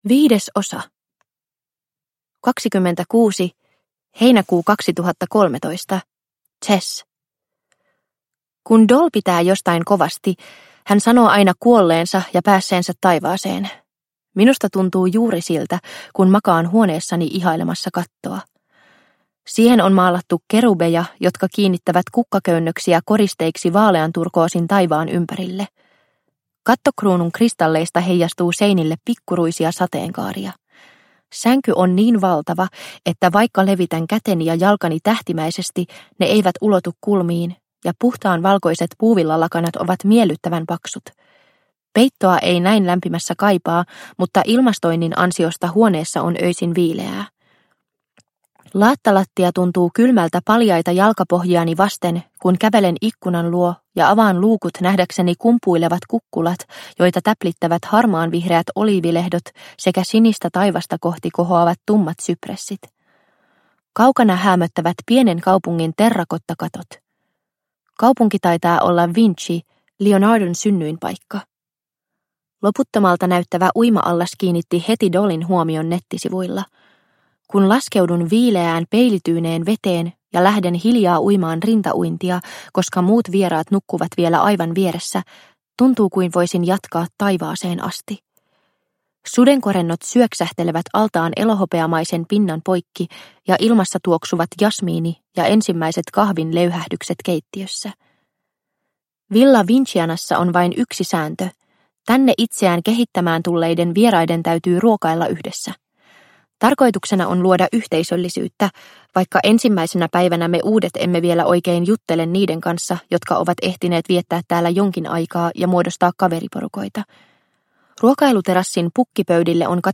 Miss You – Ljudbok – Laddas ner